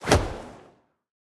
throw-projectile-6.ogg